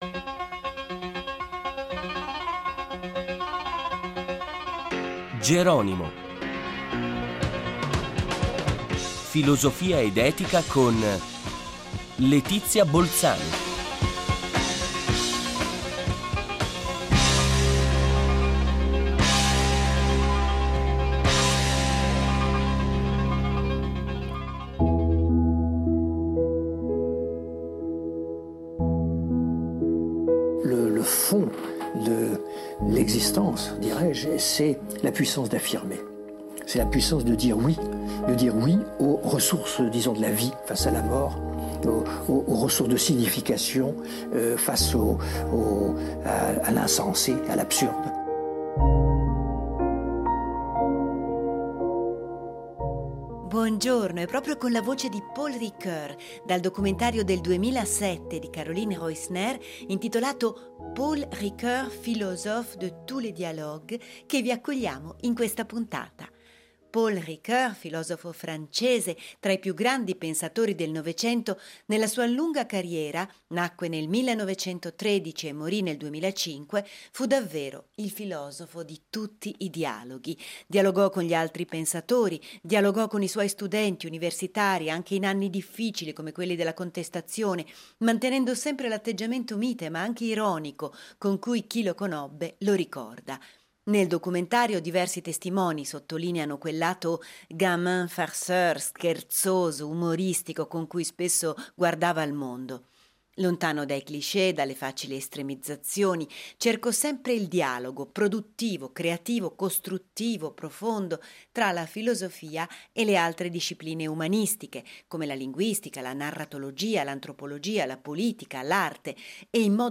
e con cui avremo il piacere di conversare in questa puntata.